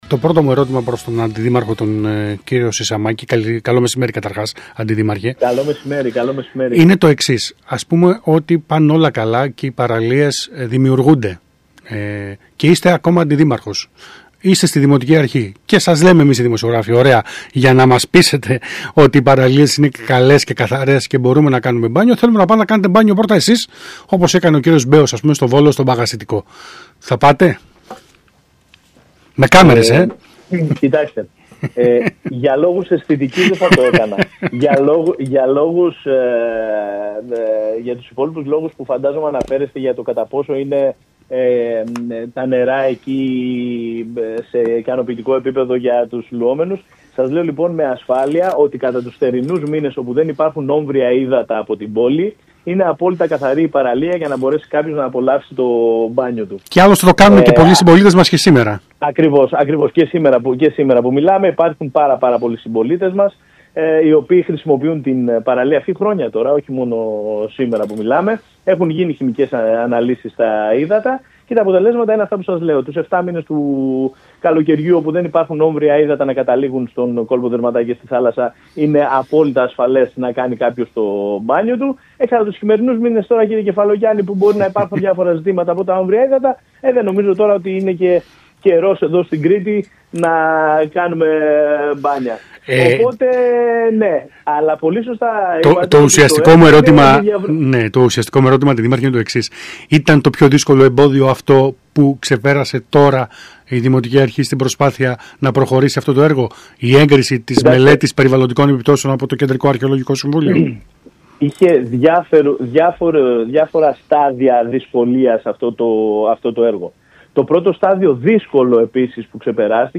Ακούστε εδώ όσα είπε στον ΣΚΑΙ Κρήτης ο Αντιδήμαρχος Γιώργος Σισαμάκης: